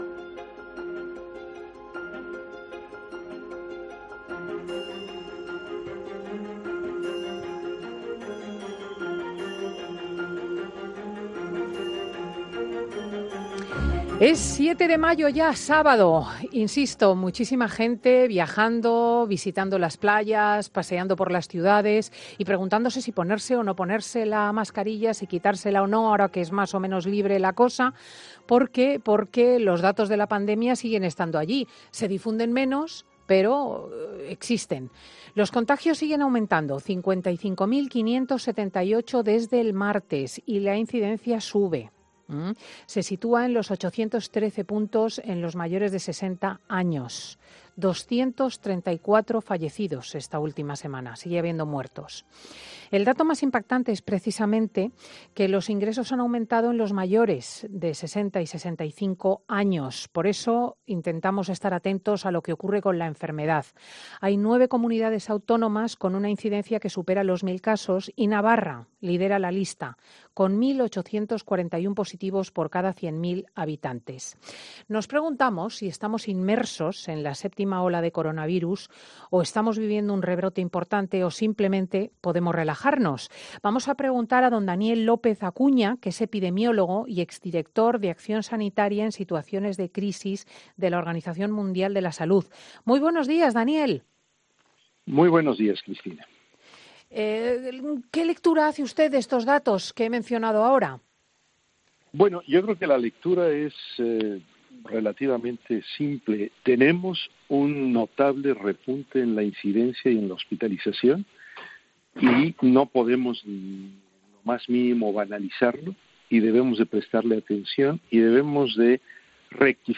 El epidemiólogo habla en 'Fin de Semana COPE' sobre la evolución de la pandemia y las nuevas variantes de Ómicron